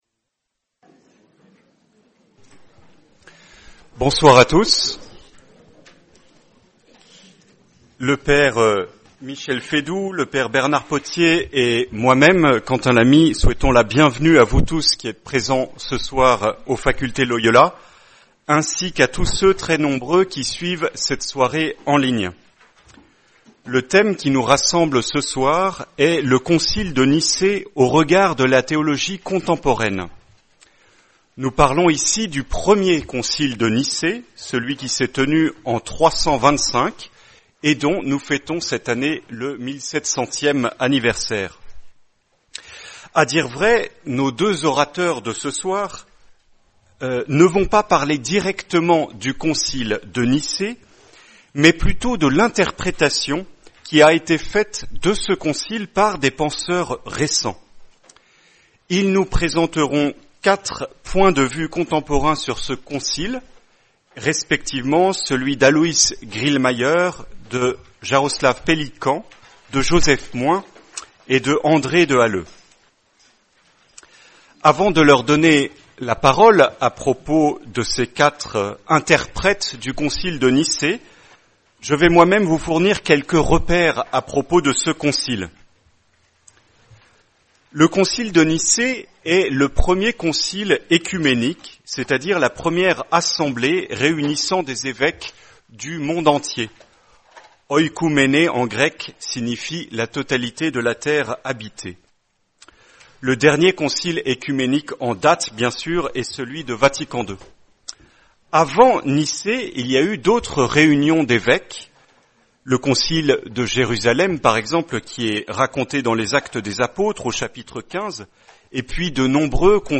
Soirée animée